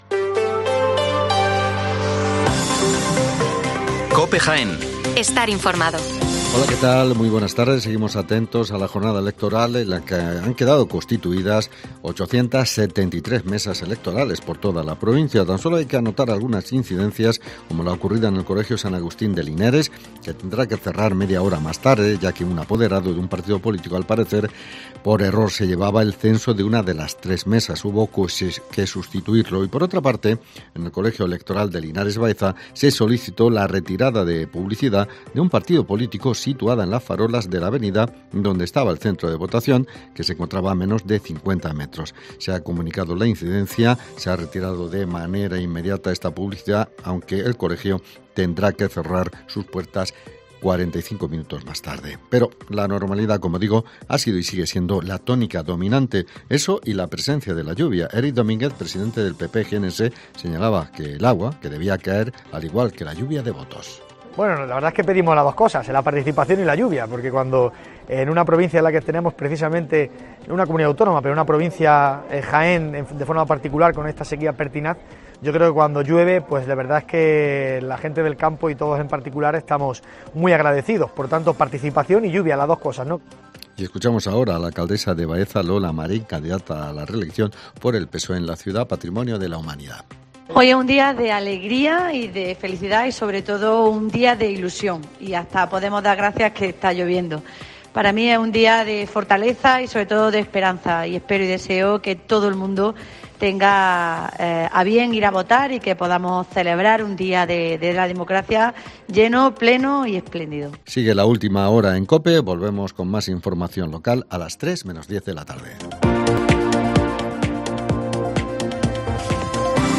Especial Elecciones Municipales en Jaén. El informativo de las 14:05 horas